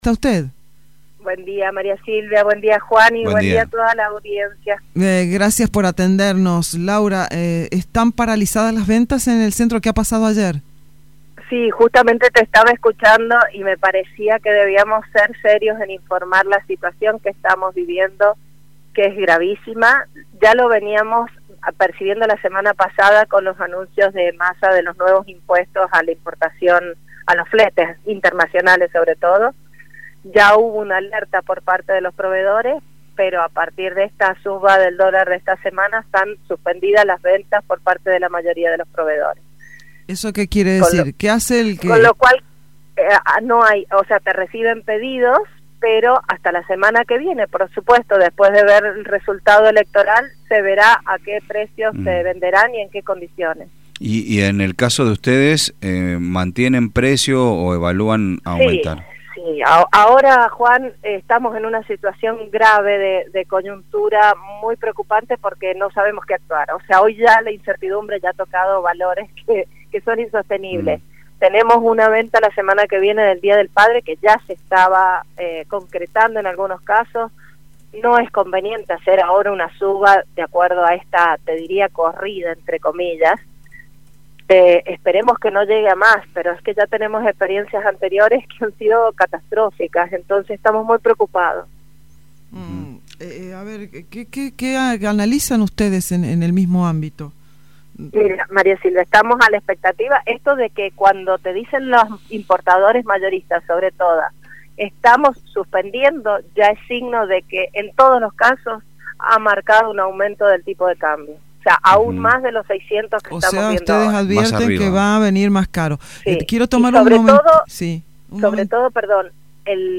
en comunicación con Radio Sarmiento